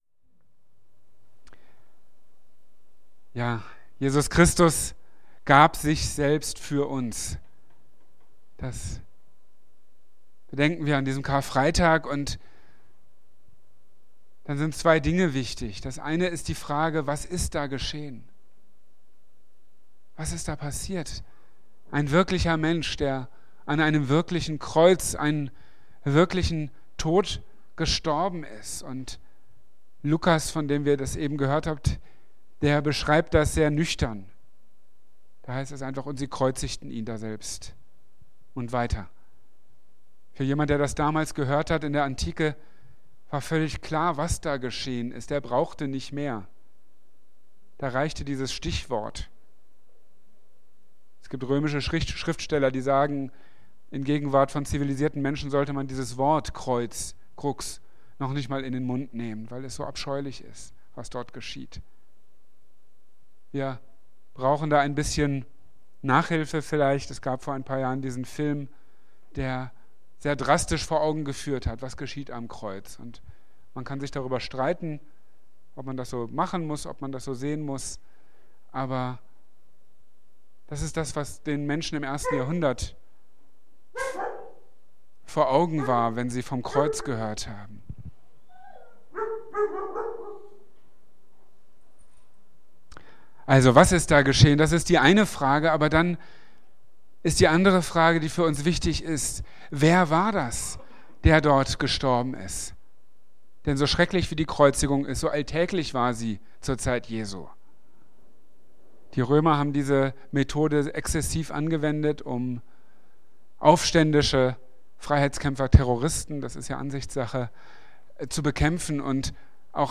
Marburger Predigten